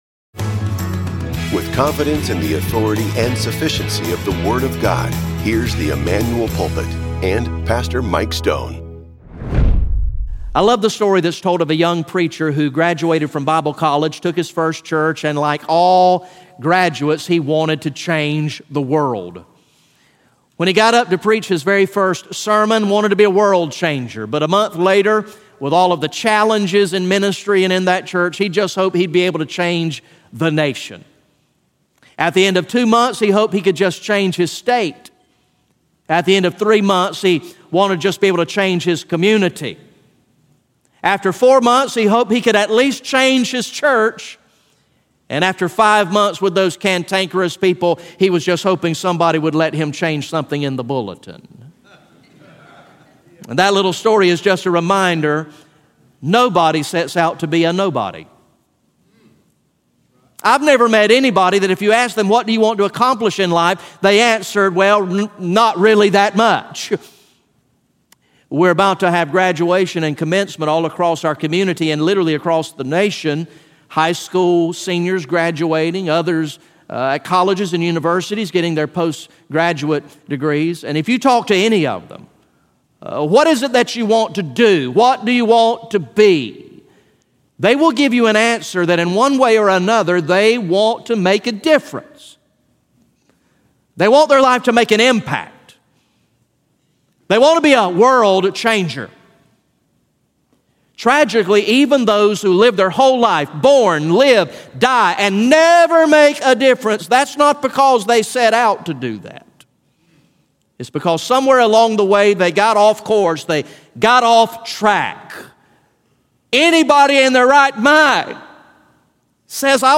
GA Message #16 from the sermon series entitled “King of Kings